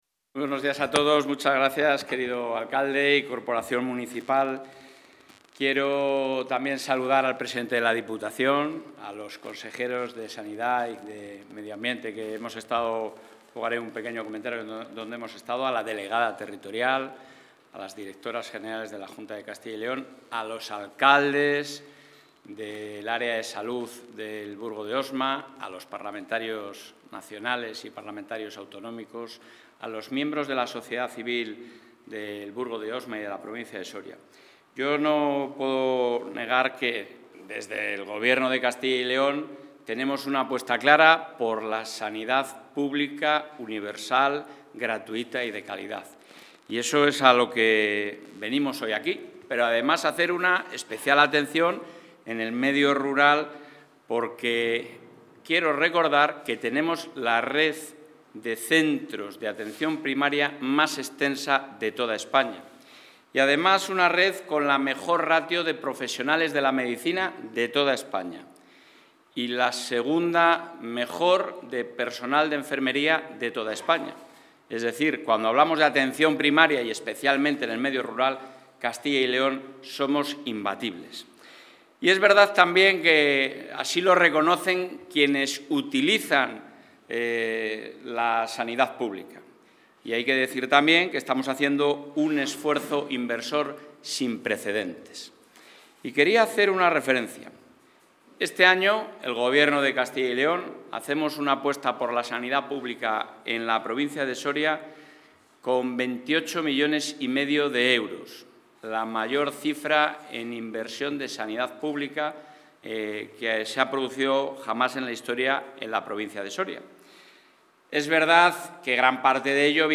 El presidente de la Junta de Castilla y León, Alfonso Fernández Mañueco, ha participado hoy en el acto de presentación...
Intervención del presidente de la Junta.